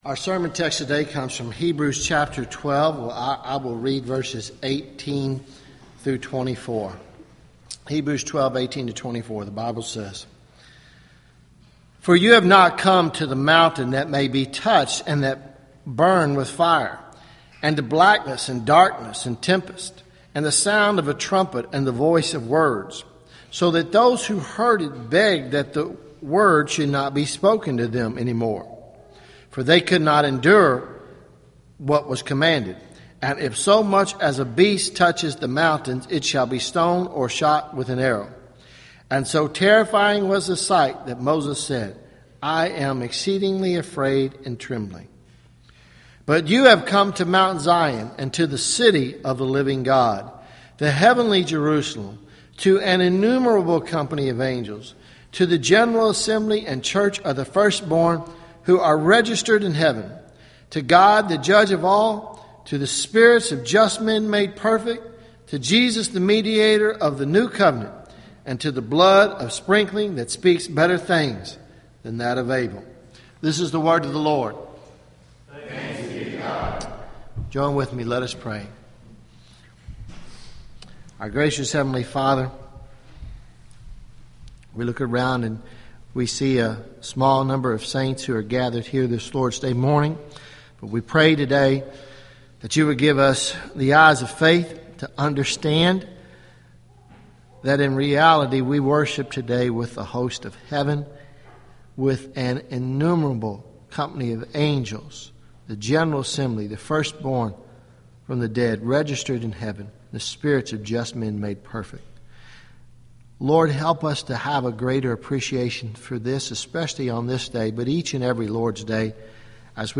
Sermons Nov 02 2014 “Memorial Day